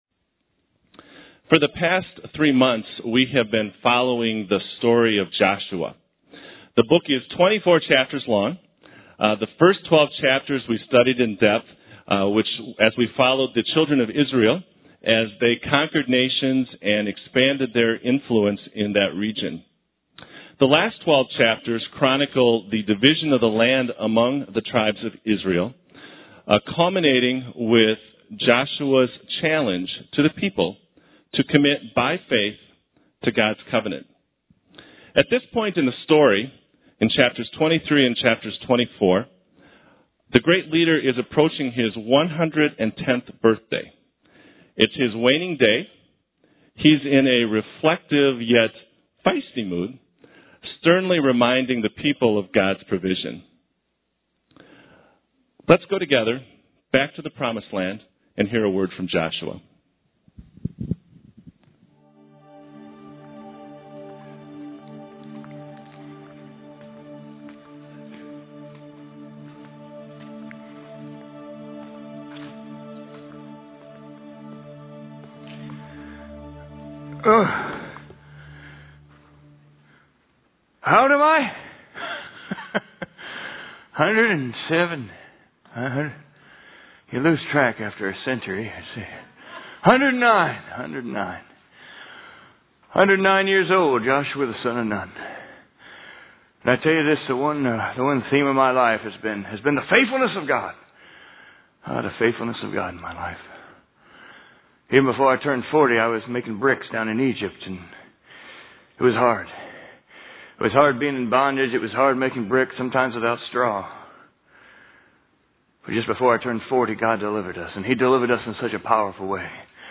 Equipping Service / Facing The Giants: Joshua and the Journey of Faith / Through the Eyes of Joshua